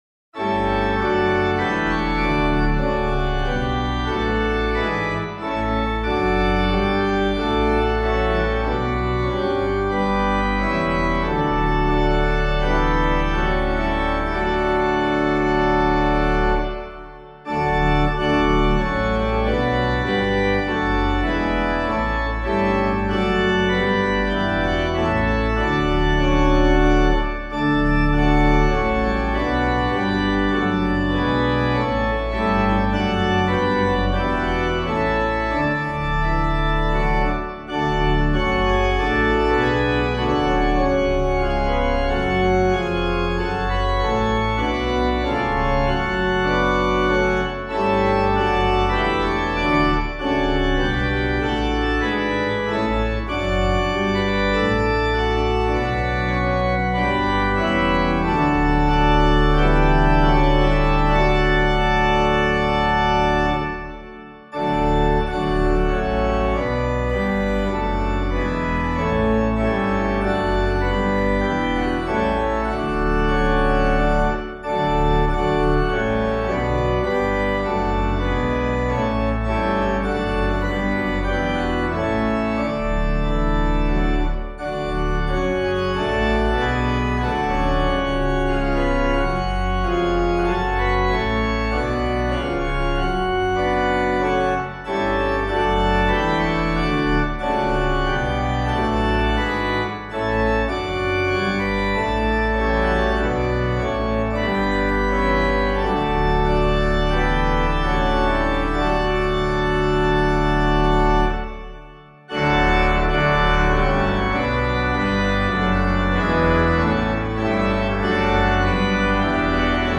Comments:    The arrangement below is somewhat simplified.
organpiano